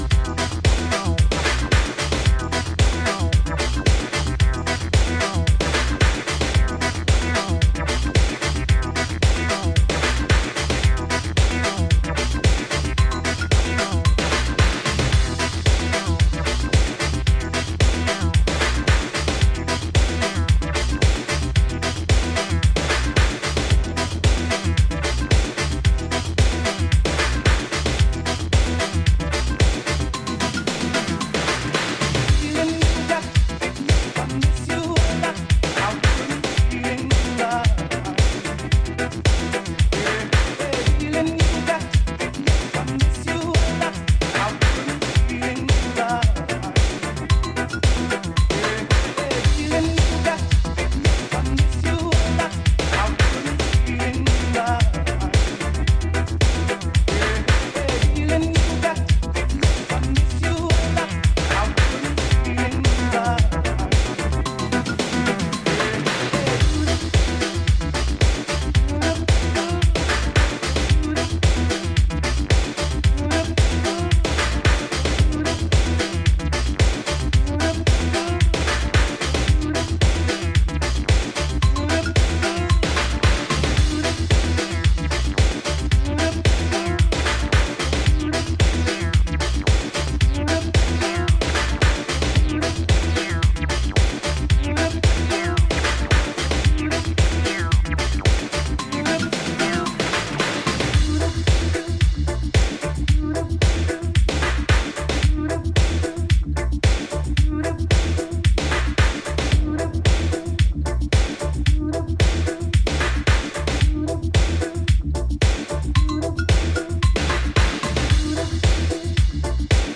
sizzling hot party music
there's a particular emphasis on acid
a serious stomp laden with wriggling 303 action